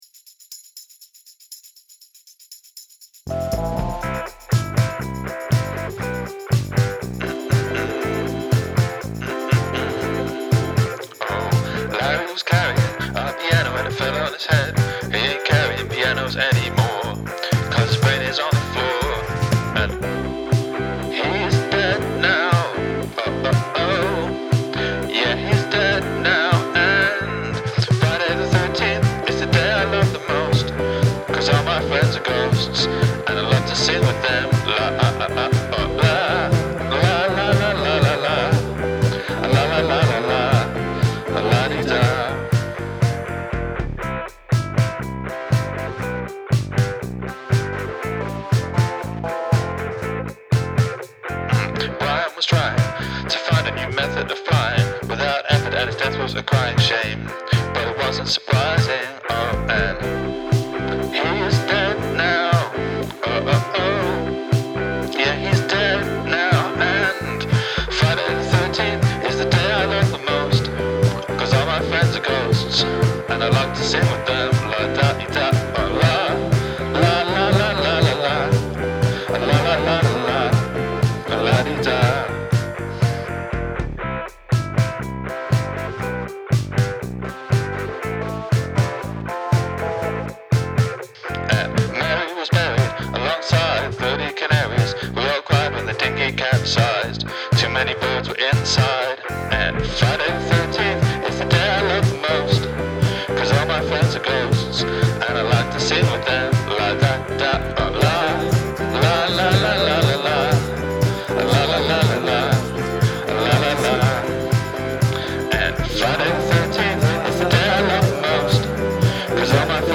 write a feel-good song with happy lyrics and upbeat music
The idea feels very forced, but that's a hell of a hook.
The chorus is nicely catchy though.